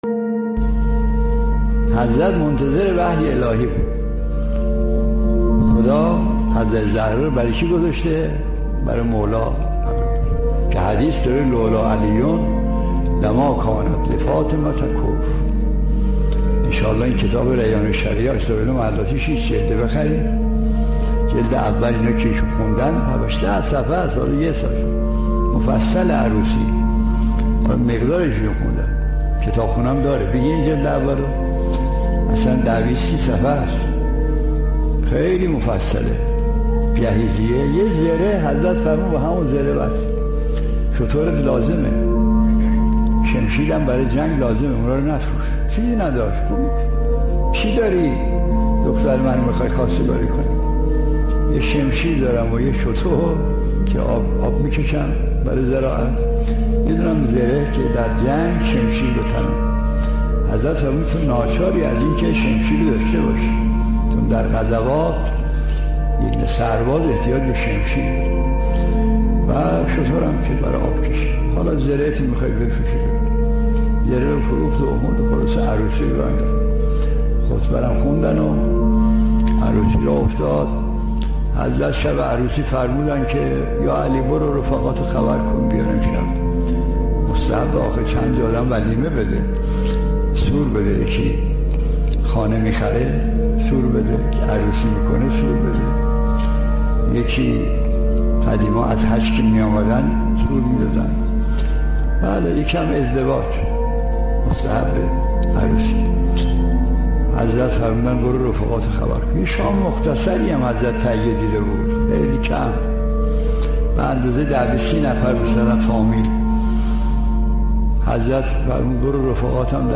به مناسبت سالروز ازدواج آسمانی حضرت علی (ع) و حضرت زهرا (س) آیت‌الله مجتهدی تهرانی از این رویداد متبرک و ازدواج آسان می‌گوید.